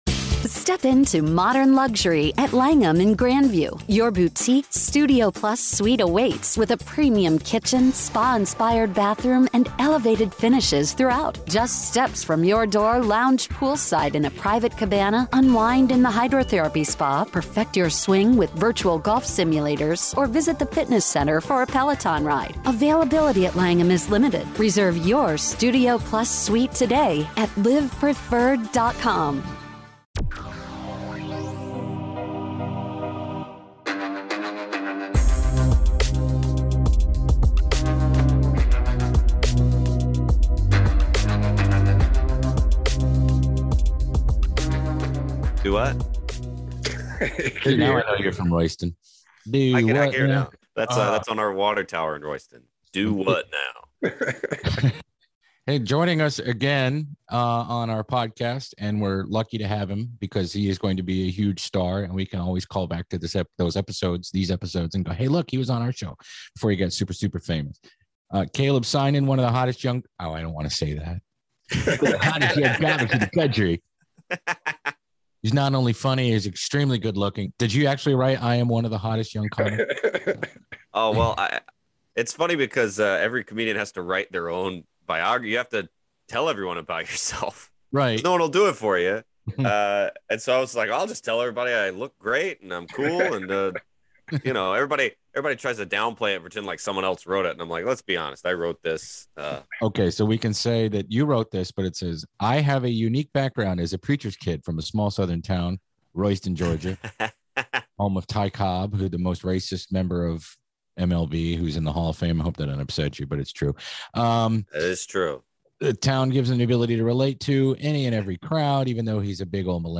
Radio Labyrinth Presents - Interviews